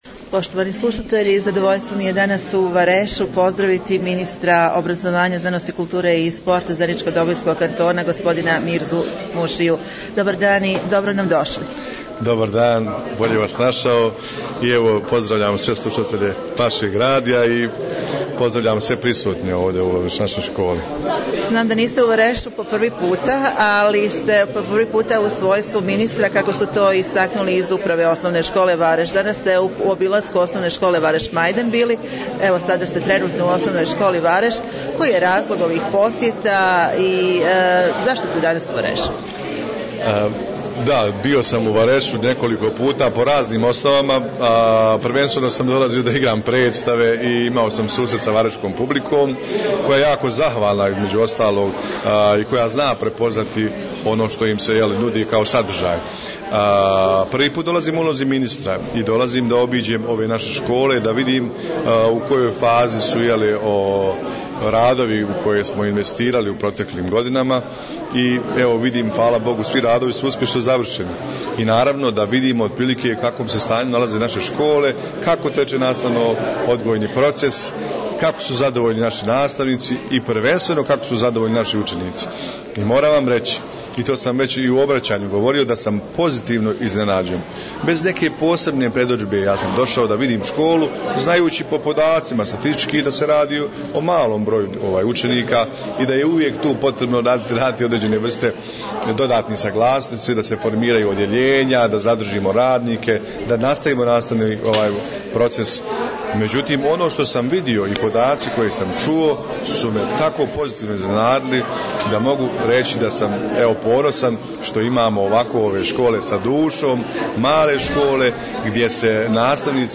Nakon posjeta Osnovnoj školi Vareš Majdan i Osnovnoj školi Vareš razgovarali smo s ministrom za obrazovanje, znanost, kulturu i sport Zeničko-dobojskog kantona Mirzom Mušijom, poslušajte ...